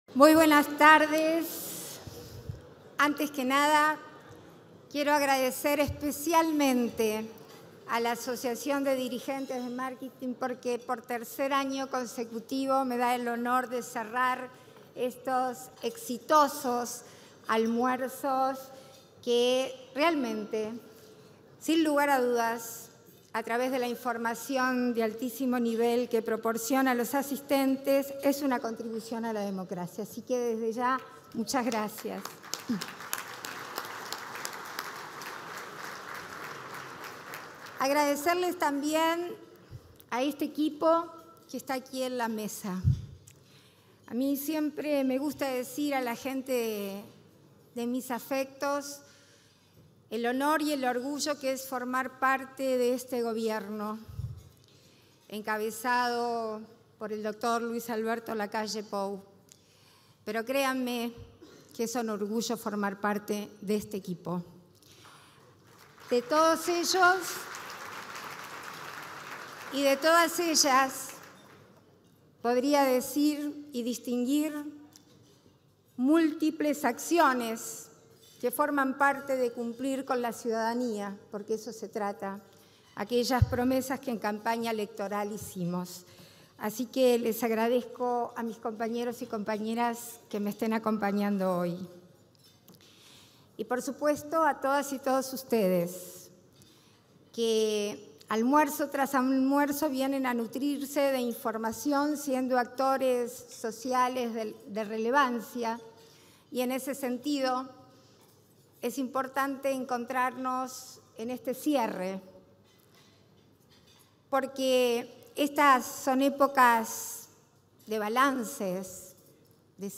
Palabras de la vicepresidenta en ejercicio de la Presidencia, Beatriz Argimón
Palabras de la vicepresidenta en ejercicio de la Presidencia, Beatriz Argimón 05/12/2023 Compartir Facebook X Copiar enlace WhatsApp LinkedIn La vicepresidenta en ejercicio de la Presidencia, Beatriz Argimón, disertó, este 5 de diciembre, en el cierre del ciclo 2023 de los almuerzos de trabajo organizados por la Asociación de Dirigentes de Marketing del Uruguay (ADM).